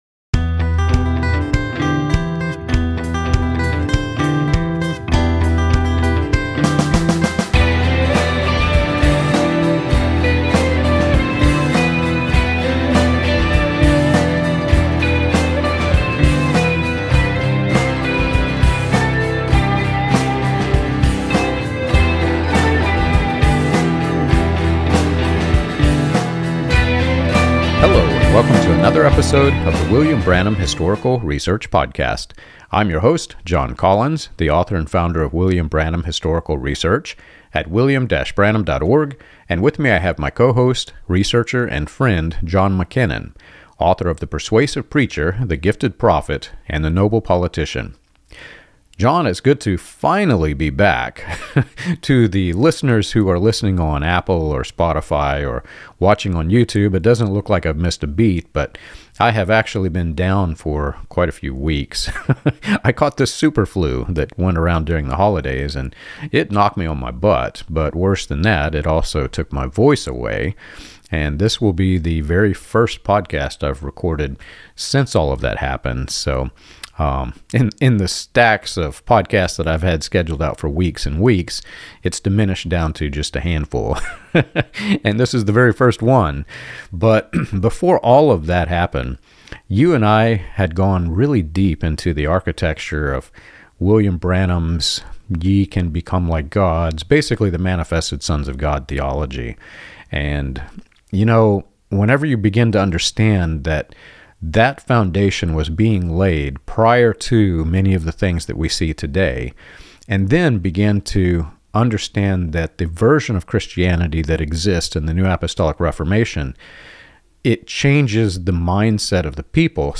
The discussion traces how language about power, manifestation, and authority replaces repentance, redemption, and resurrection, and why Scripture consistently presents salvation as God's work from beginning to end. By grounding the conversation in clear biblical texts, this episode helps listeners discern the difference between adoption by grace and systems that promise elevation through spiritual status.